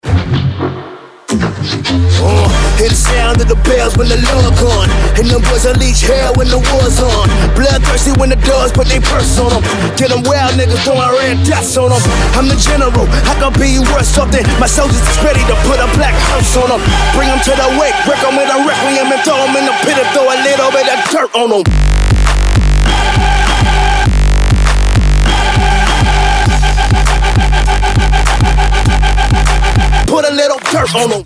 • Качество: 256, Stereo
мощные
Electronic
Trap
Bass